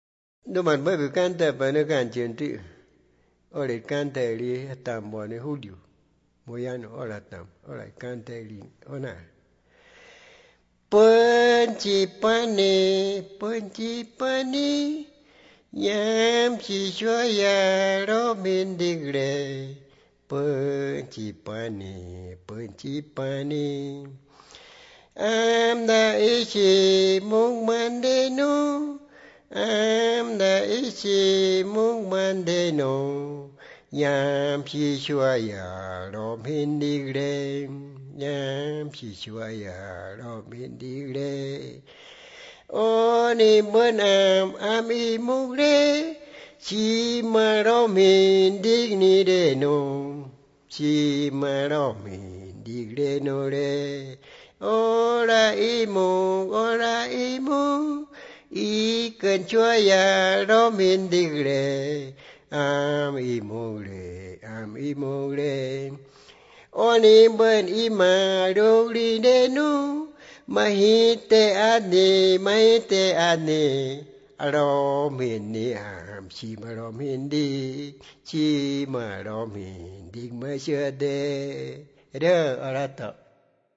La canción habla de los concho de la chica relacionándolo con la gente 'bocón grande' y con la gente 'guaracú' con cierta añoranza. La grabación, transcripción y traducción de la canción se hizo entre marzo y abril de 2001 en Piedra Alta; una posterior grabación en estudio se realizó en Bogotá en 2004
Canciones Wãnsöjöt